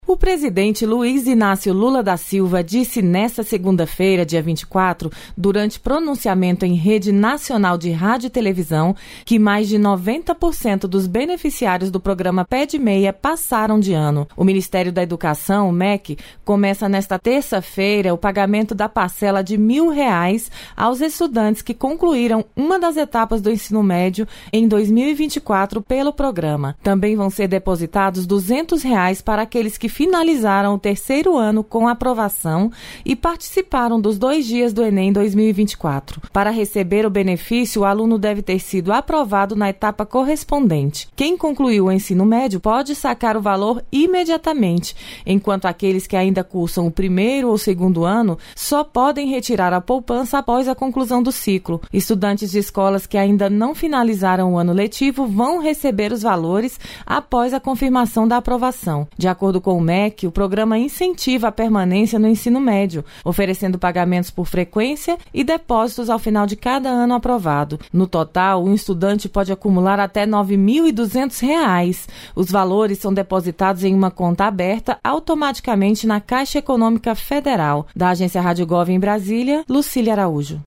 É Notícia
Dado foi anunciado pelo presidente durante pronunciamento em rede nacional de rádio e televisão.